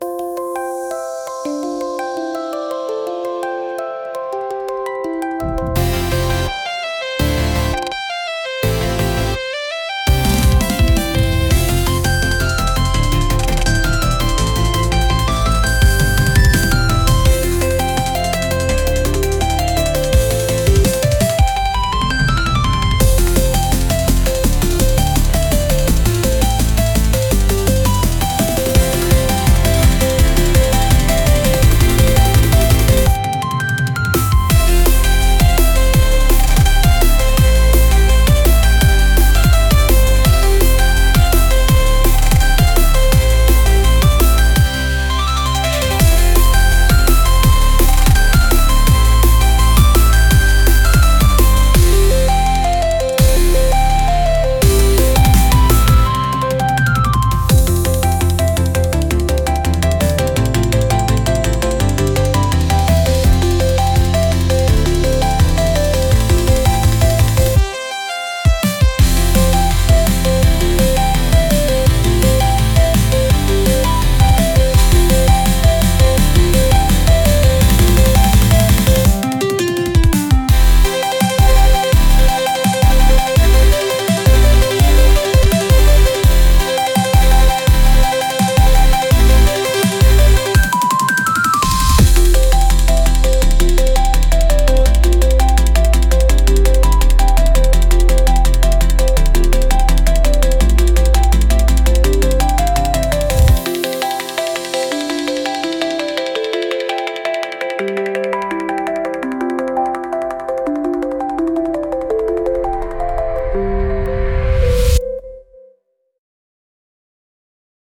Genre: Hyperpop Mood: Energetic Editor's Choice